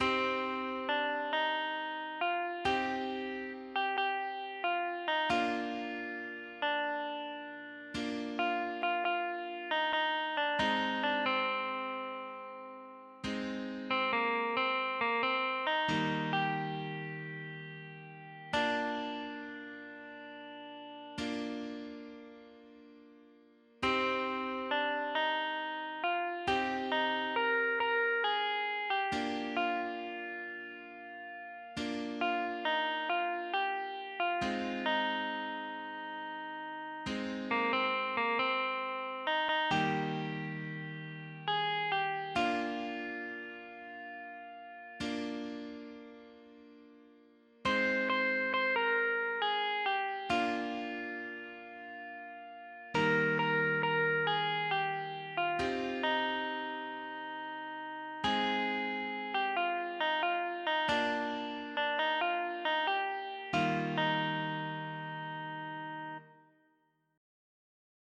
(Via Crucis cantado)